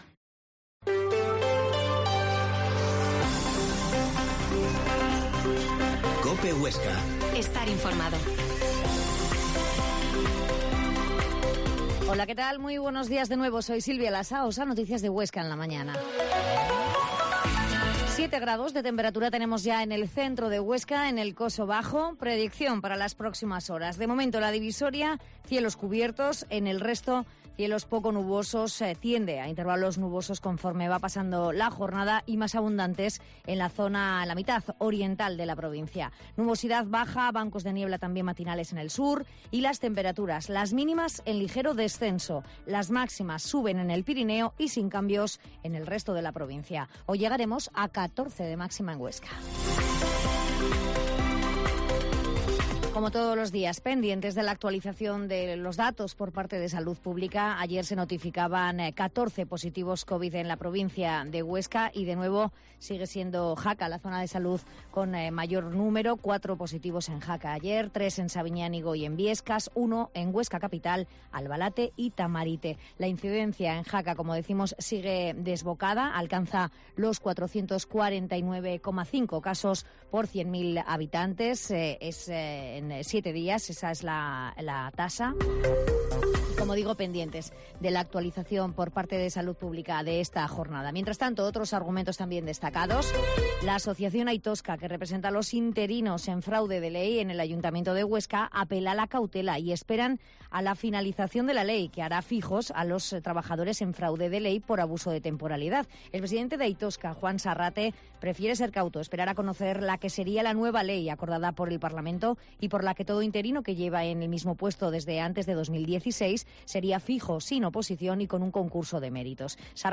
La Mañana en COPE Huesca - Informativo local